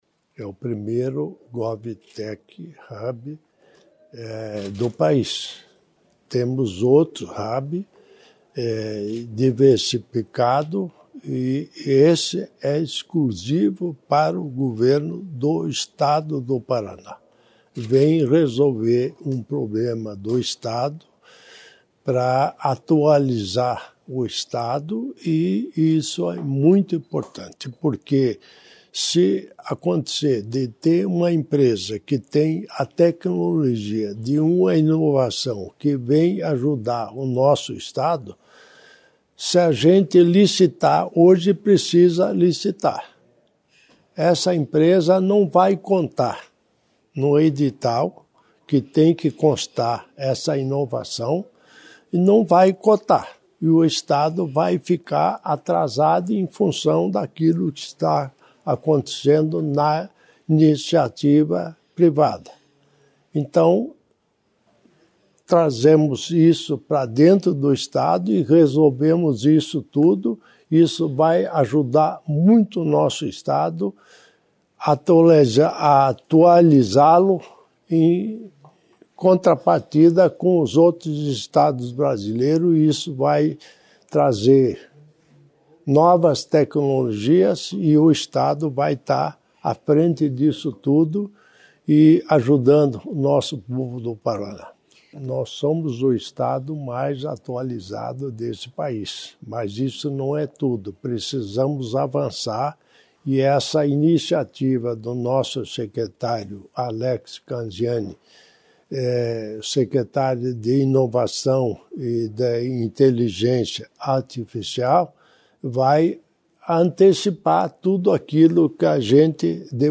Sonora do governador em exercício Darci Piana sobre o hub de GovTechs que vai ser instalado em Curitiba